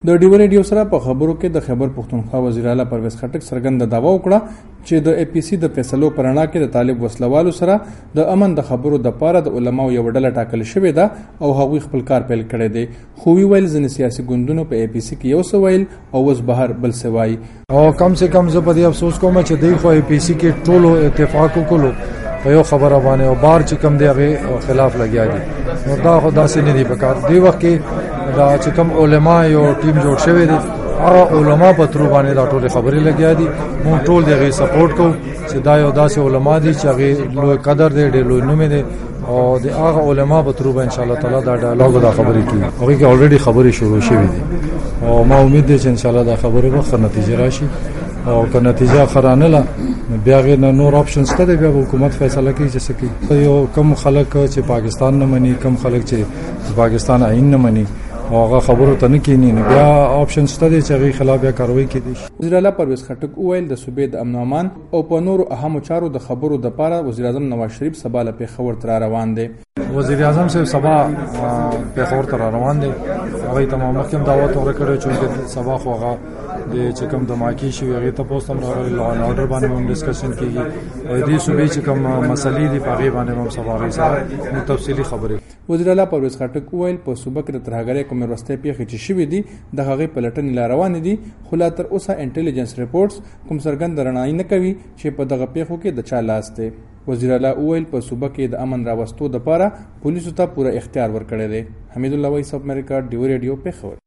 د وزیراعلا پرویزخټک مرکه